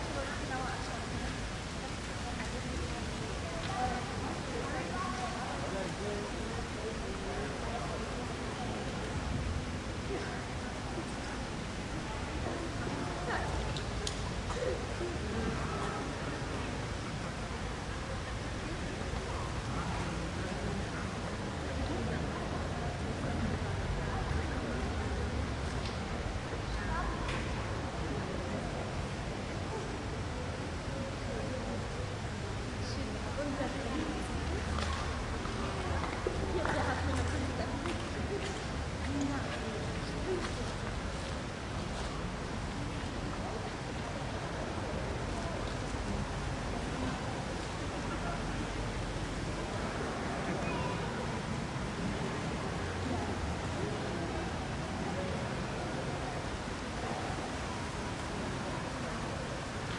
住宅区 立体声环境
描述：一个住宅区，遥远的街头咖啡馆，懒惰的街道，夏天在城市
标签： 背景声 气氛 环境 背景 ATMOS 气氛 气氛 ATMO 现场记录 立体声
声道立体声